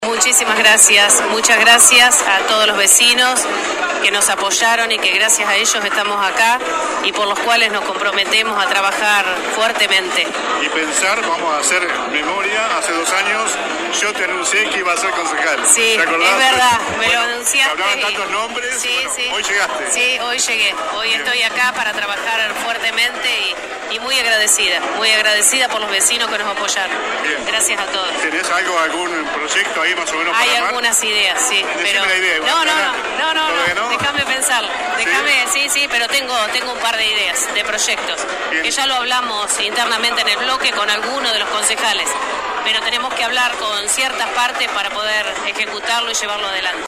(incluye audios) Los flamantes ediles dejaron este viernes en la 91.5 su reflexión por lo vivido en el marco de la sesión preparatoria que tuvo lugar en la tarde del jueves en el salón «Dr. Oscar Alende» del HCD.